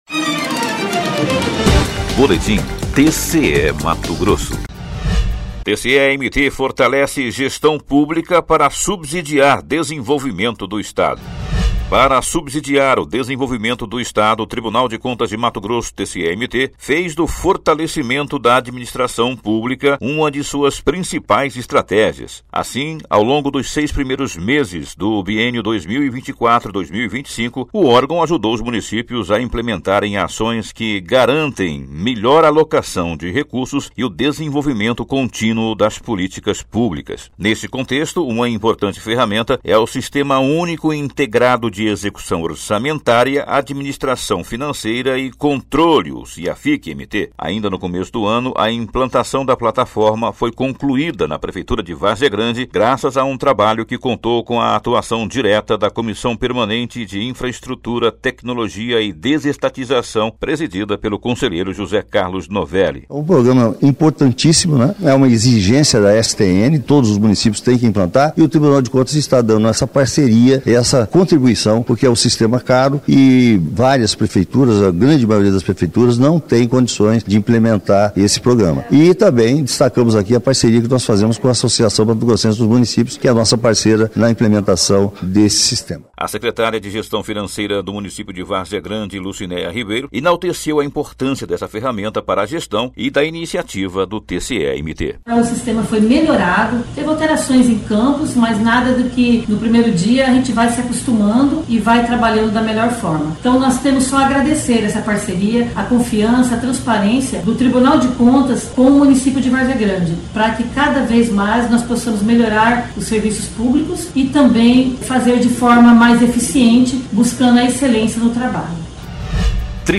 Sonora: José Carlos Novelli – presidente da Comissão Permanente de Infraestrutura, Tecnologia e Desestatização
Sonora: Lucinéia Ribeiro - secretária de Gestão Fazendária de Várzea Grande